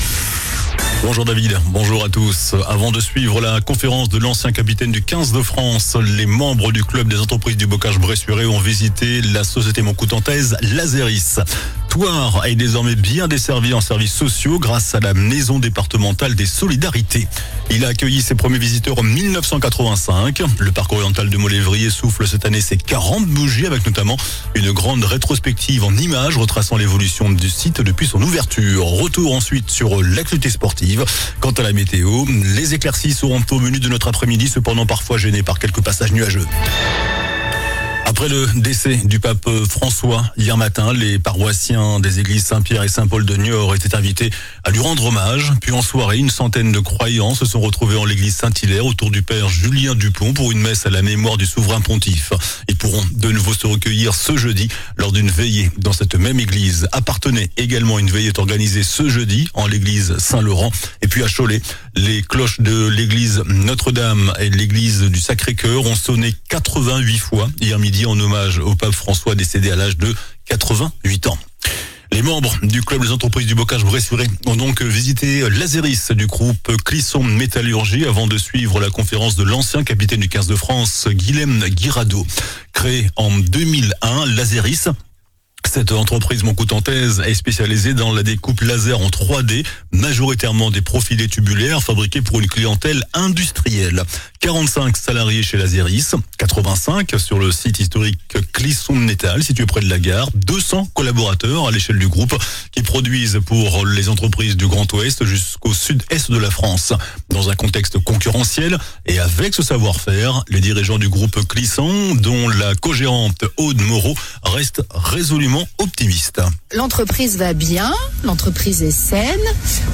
JOURNAL DU MARDI 22 AVRIL ( MIDI )